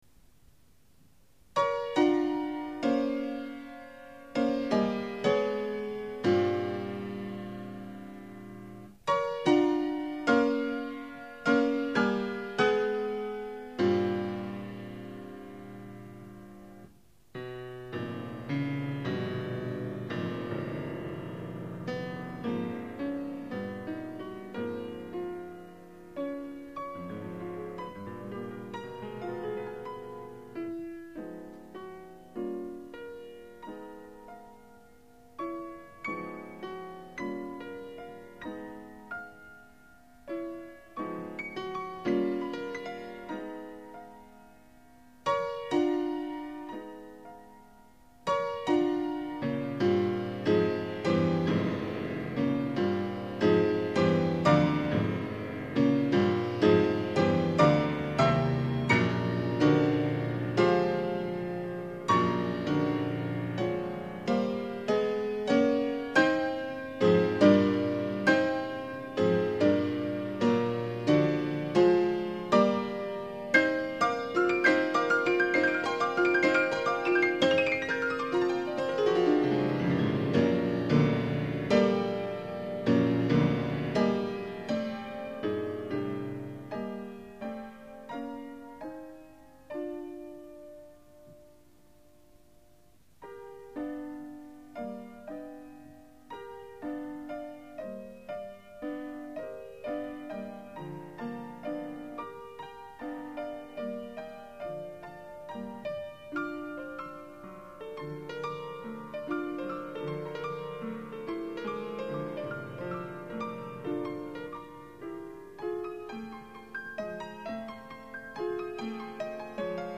自演